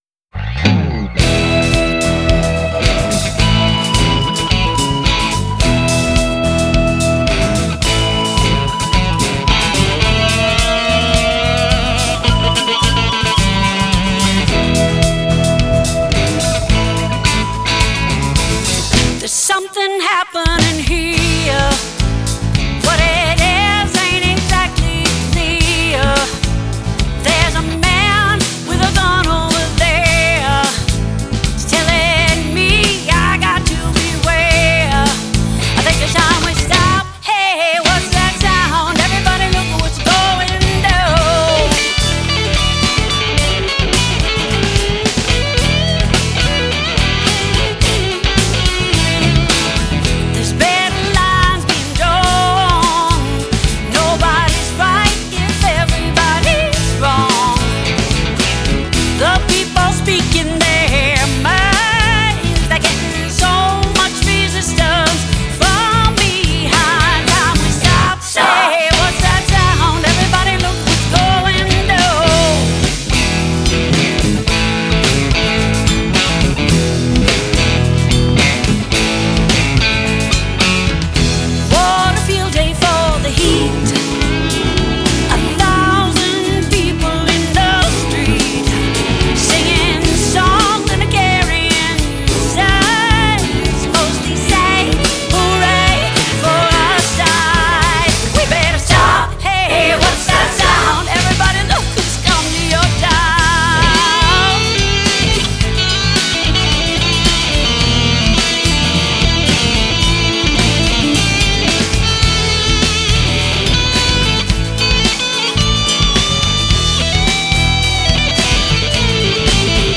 acoustic guitar and vocals
electric guitar
bass
drums